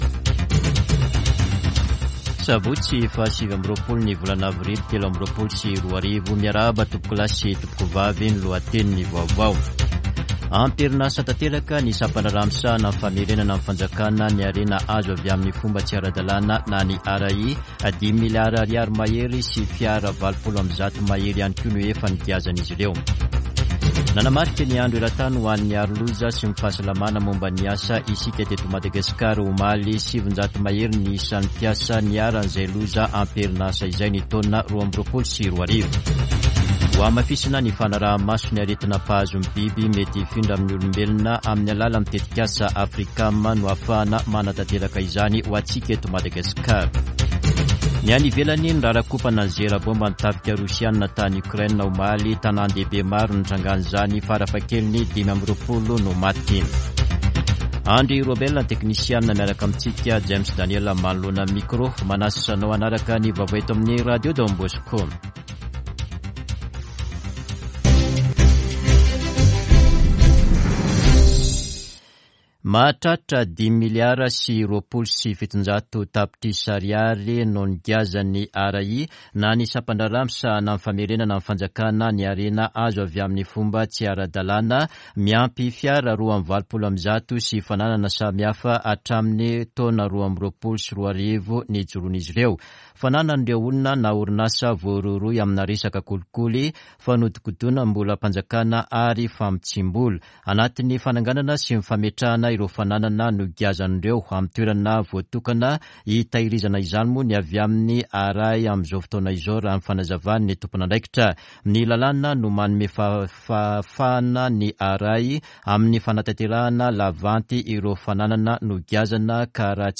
[Vaovao maraina] Sabotsy 29 avrily 2023